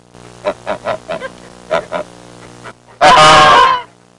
Gooses Sound Effect
gooses.mp3